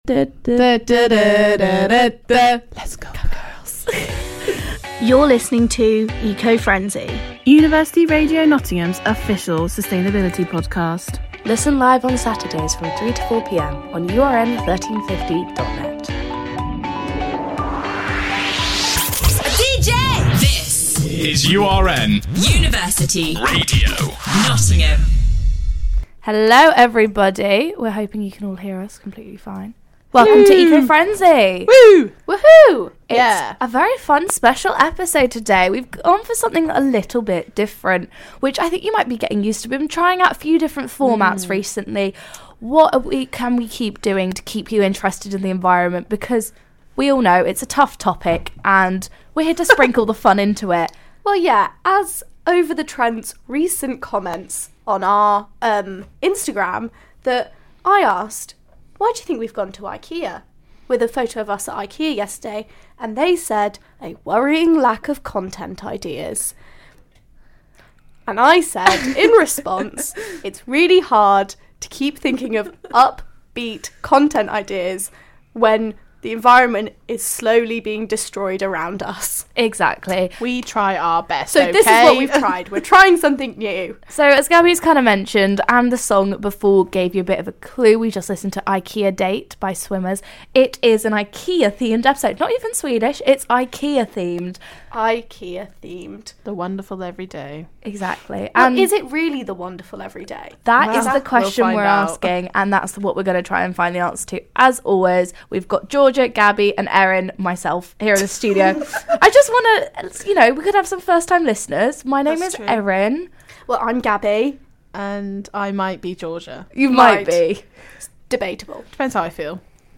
~ Originally broadcast live on University Radio Nottingham on 25th February 2023.